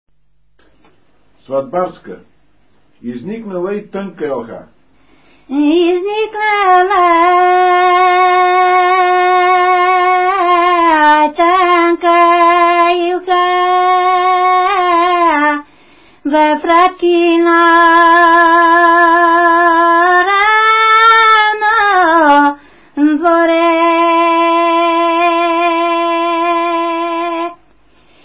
музикална класификация Песен
форма Двуредична
размер Безмензурна
фактура Едногласна
начин на изпълнение Солово изпълнение на песен
битова функция На сватба
фолклорна област Средна Северна България
начин на записване Магнетофонна лента